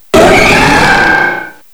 cry_not_mega_heracross.aif